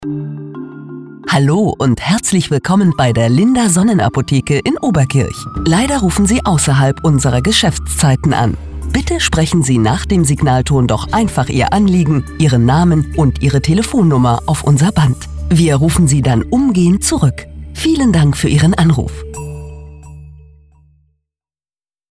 Telefonansage Apotheke – Ausserhalb der Geschäftszeiten
AB-Ansage-Apotheke.mp3